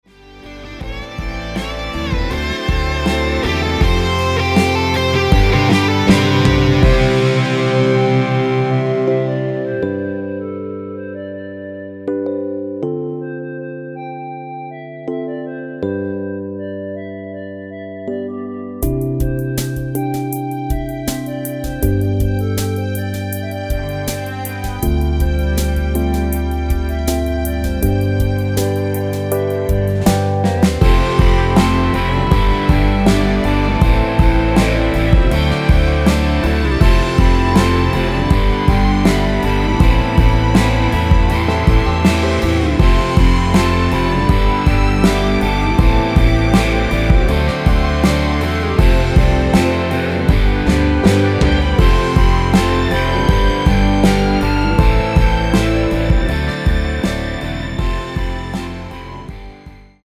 원키 멜로디 포함된 MR입니다.(미리듣기 확인)
앞부분30초, 뒷부분30초씩 편집해서 올려 드리고 있습니다.
중간에 음이 끈어지고 다시 나오는 이유는
(멜로디 MR)은 가이드 멜로디가 포함된 MR 입니다.